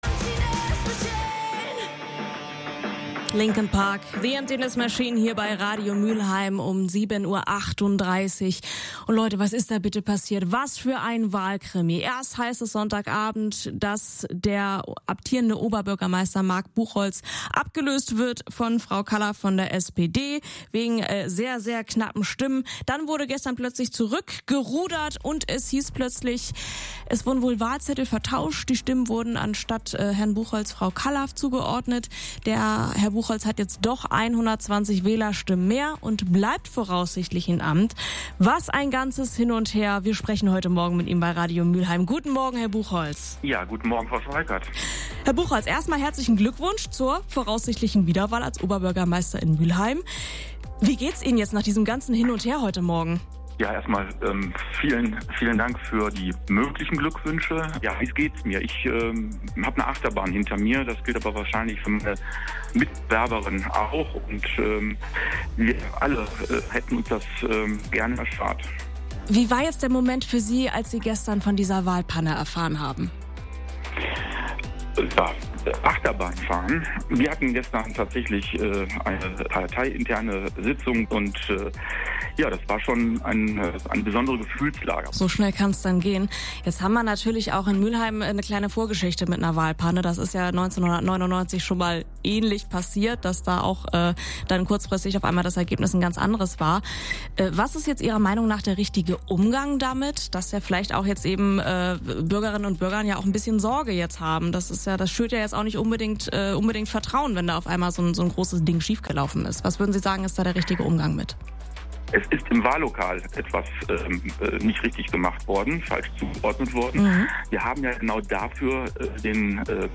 In Mülheim bleibt der bisherige Oberbürgermeister Marc Buchholz nun doch in seinem Amt. Es gab bei der Auszählung der Stimmzettel eine Panne. Ein exklusives Interview findet ihr weiter unten.